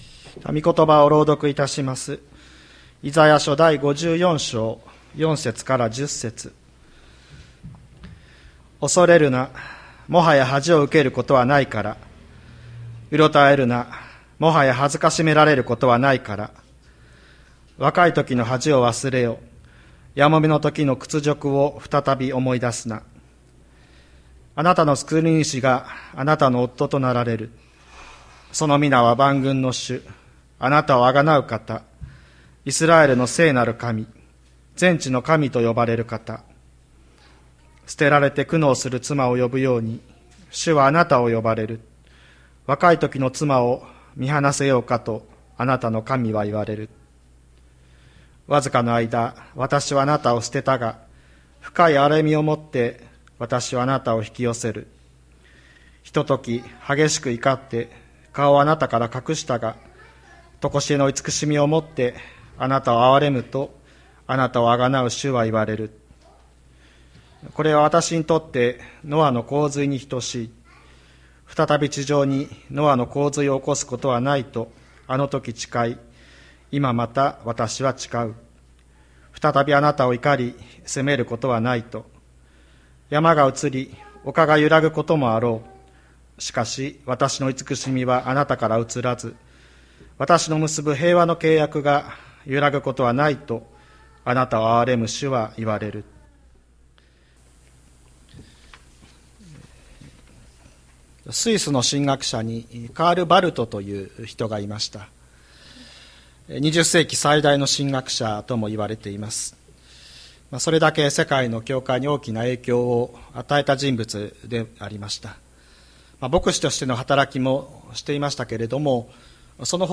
2023年04月02日朝の礼拝「ほんの一瞬」吹田市千里山のキリスト教会
千里山教会 2023年04月02日の礼拝メッセージ。